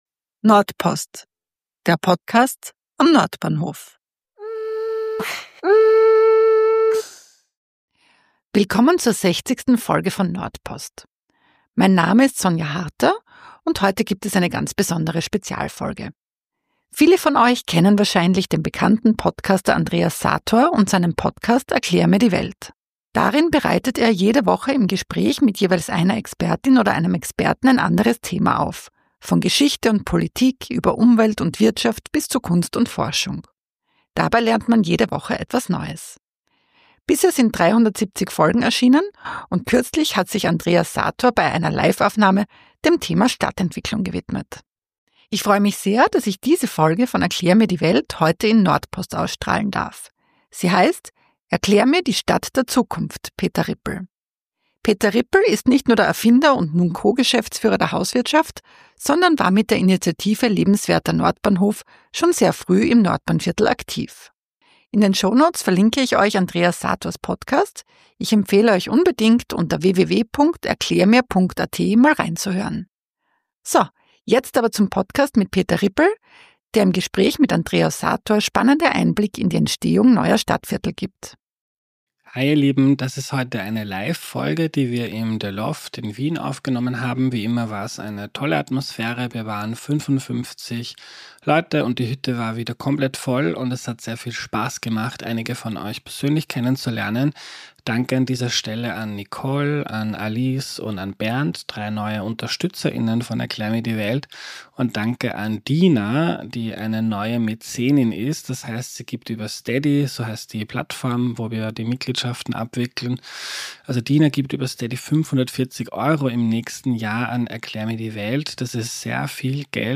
bei einer Live-Aufnahme